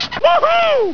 "WoooHooooo!!" when she is really happy and excited by her surroundings!
woohoo.wav